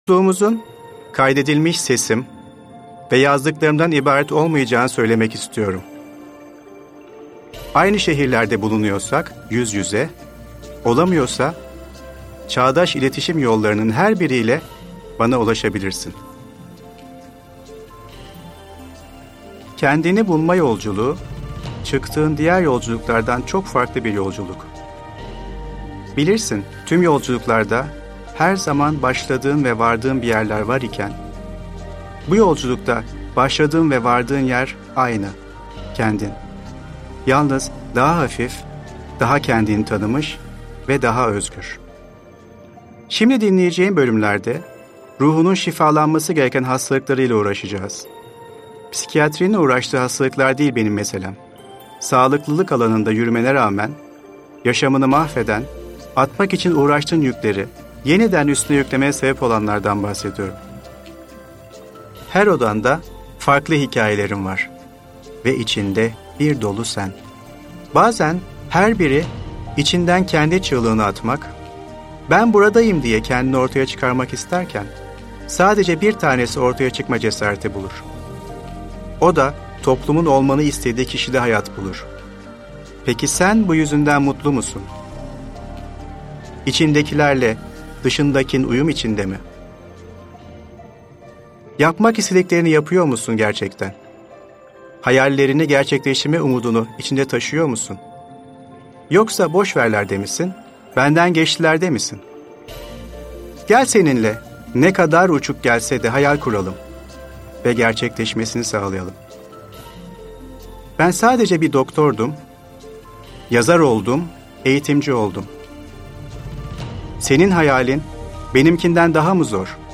Şimdi’nin sırrını öğrenmek ve anda kalmak için bu meditasyon yardımcı olacaktır. (Bu meditasyon binaural kayıt ile oluşturulduğundan kulaklık ile dinlenmesi tavsiye olunur) İlahi Alanla Bağlantı Meditasyonu: Bizler ilahi olan ile maddesel olanın arasında köprü vazifesi görenleriz.